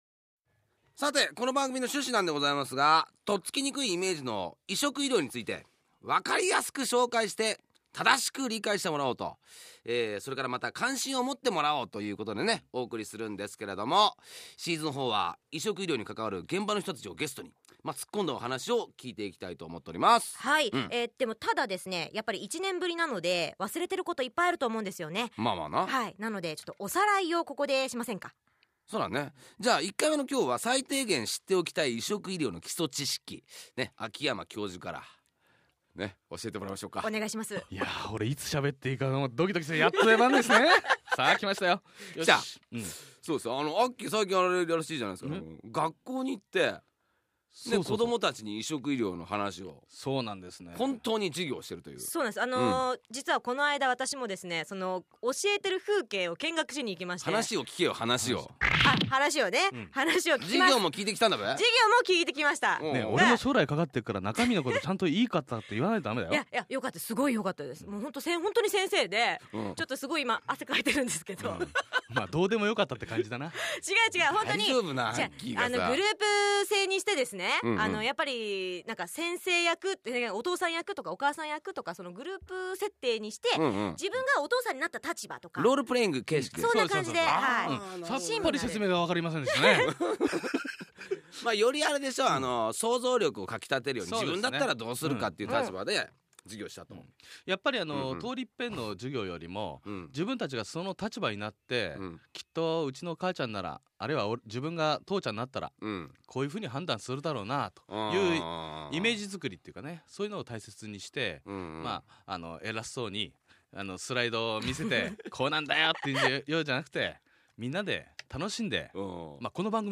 ※BGMやリクエスト曲、CMはカットしています。